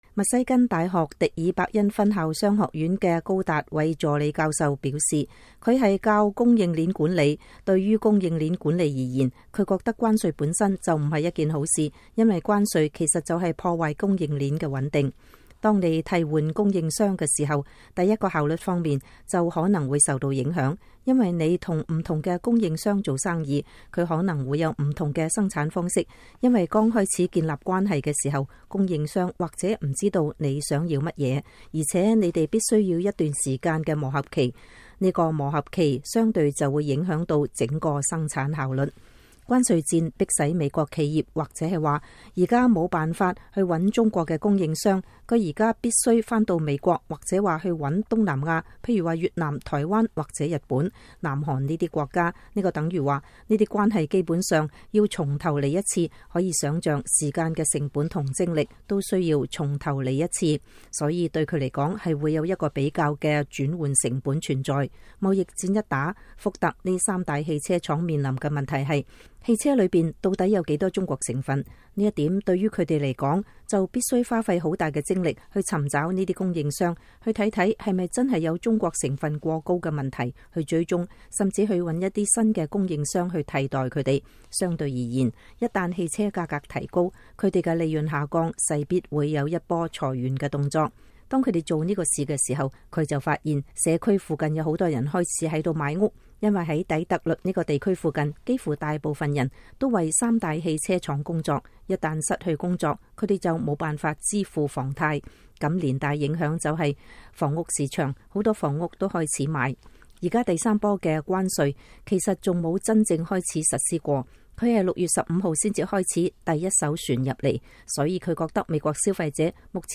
(美國之音進行一系列採訪，反映有關美中關係及美國政策的負責任的討論和觀點。被採訪人所發表的評論並不代表美國之音的立場。)